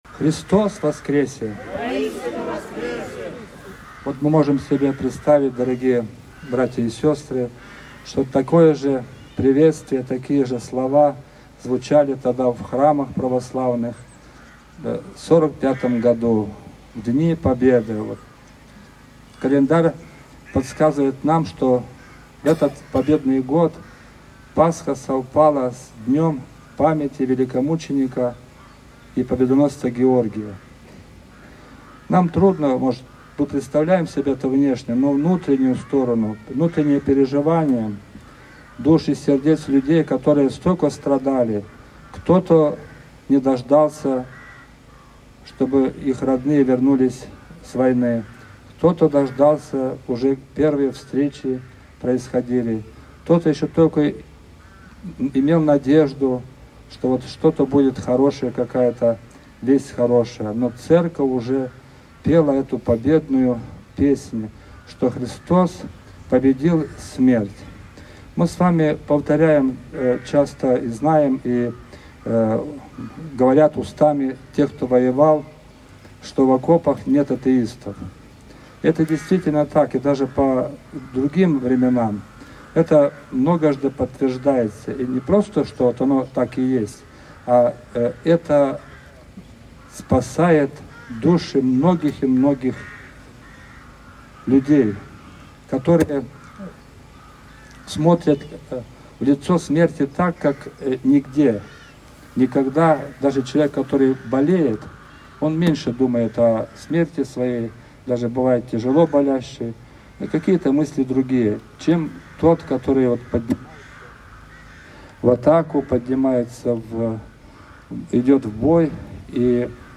Песнопения исполнял мужской квартет
Завершился день праздничным концертом на прихрамовой территории, в ходе которого звучали песни военных лет. Торжественный колокольный звон прославлял Великую Победу. Перед гостями также выступил хор педагогов Красносельского района «Созвездие».